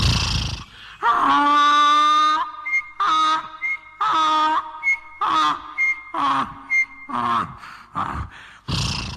دانلود آهنگ خر از افکت صوتی انسان و موجودات زنده
دانلود صدای خر از ساعد نیوز با لینک مستقیم و کیفیت بالا
جلوه های صوتی